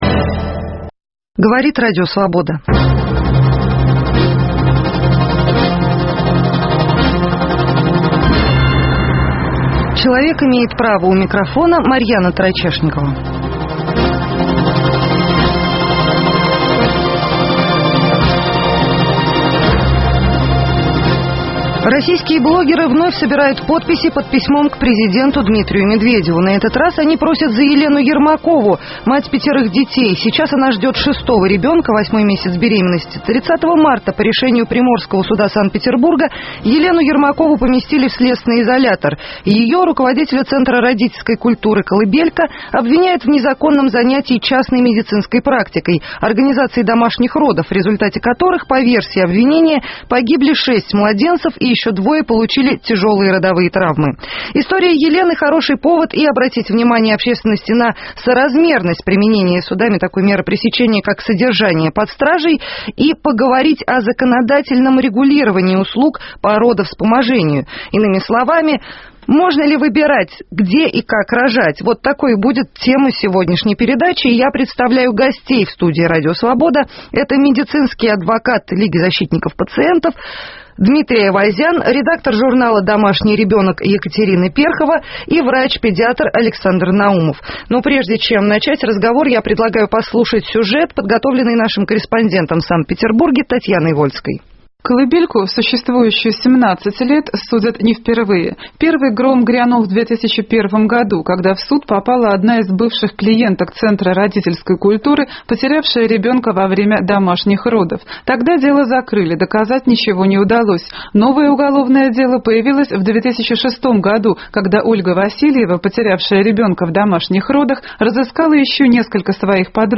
Законодательное регулирование услуг по родовспоможению на дому. Может ли в России женщина выбирать, где и как рожать, и вправе ли частнопрактикующие специалисты оказывать помощь в родах на дому, не рискуя подвергнуться уголовному преследованию? В студии РС: